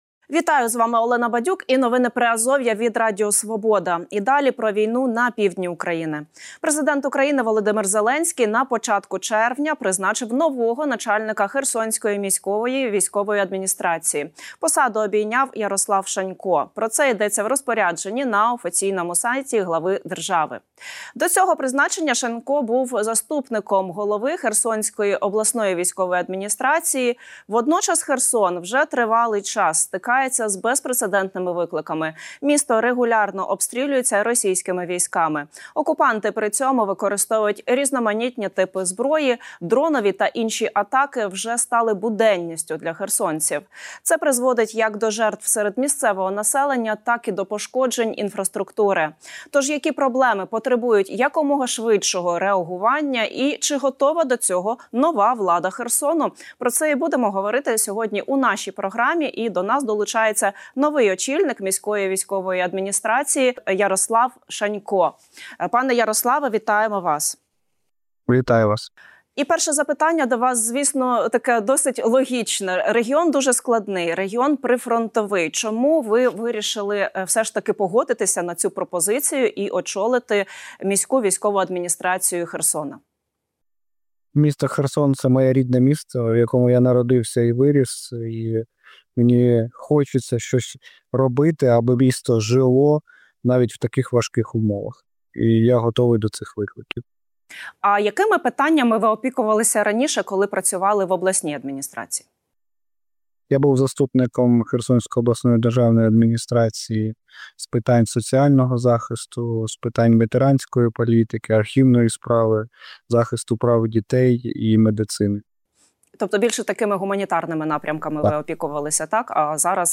Інтерв'ю з новим начальником Херсонської МВА Ярославом Шаньком | | Новини Приазов'я